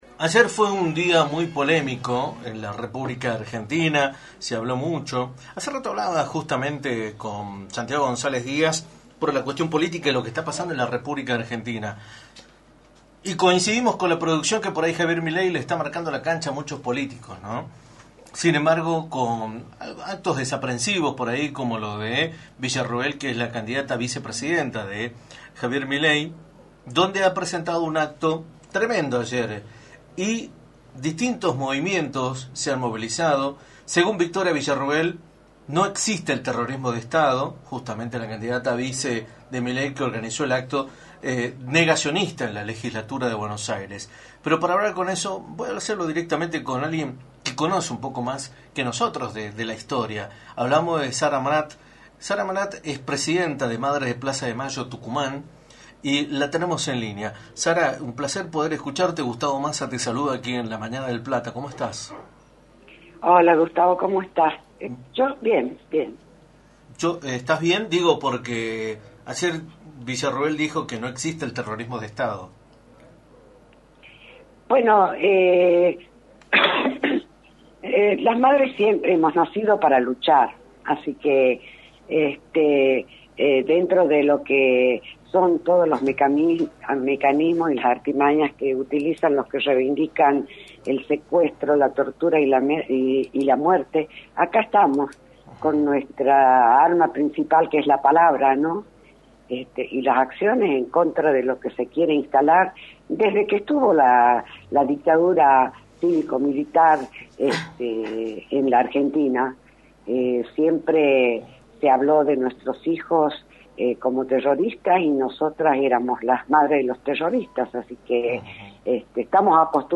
en entrevista para “La Mañana del Plata”, por la 93.9.